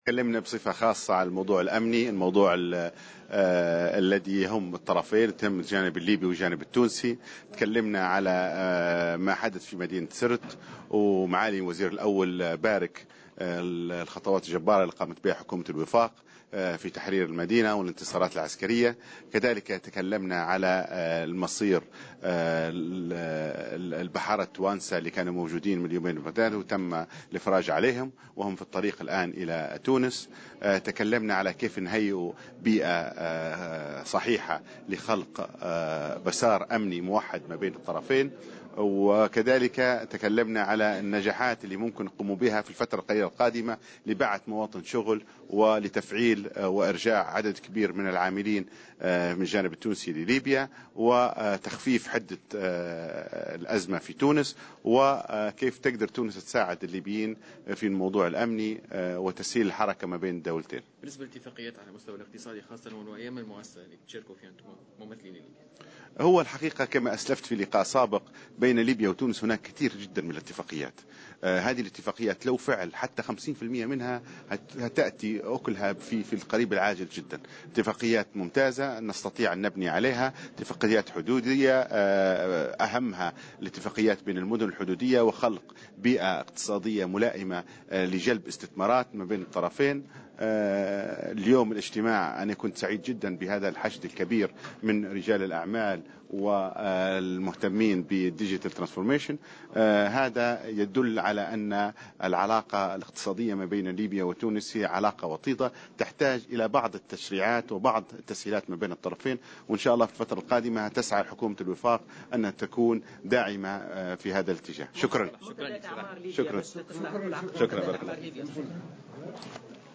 وأكد معيتيق في تصريح إعلامي على هامش أشغال الدورة 31 لأيام المؤسسة الذي ينعقد بسوسة على أهمية دعم المشاريع المشتركة بالمناطق الحدودية وتسهيل الحركة بين البلدين.